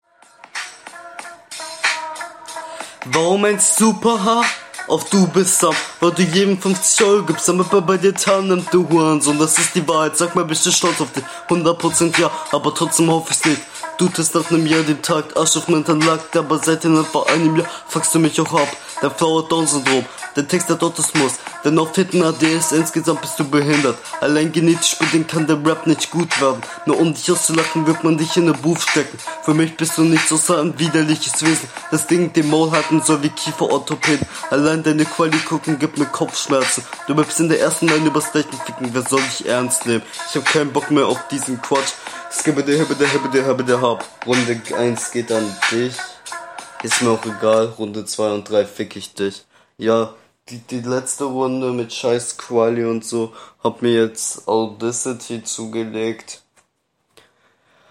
Flow: es ist schwer zu beurteilen, weil der Beat nicht so gut hörbar ist.
Der Beat wurde auf Lautsprechern abgespielt und mit aufgenommen, Ein Flow ist ansatzweise vorhanden, wenigstens …
Flow: Der Flow wirkt leider noch relativ unroutiniert und monoton in deiner Audio.